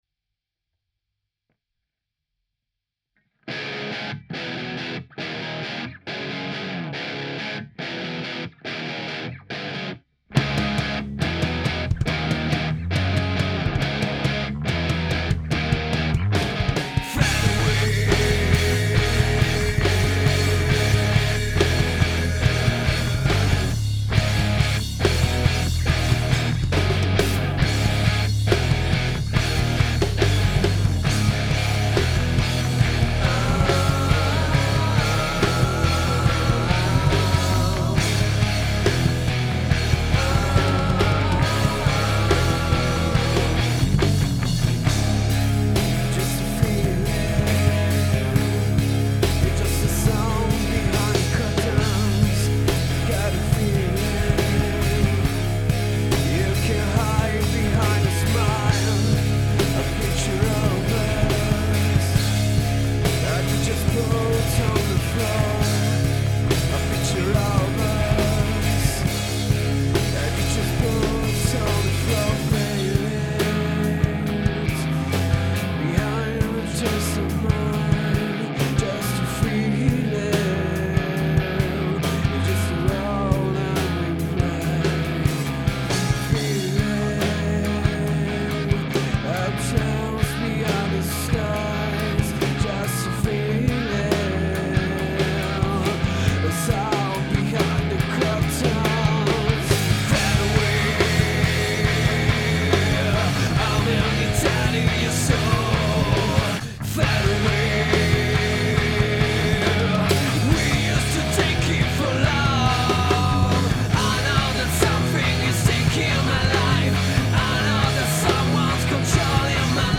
Un mix :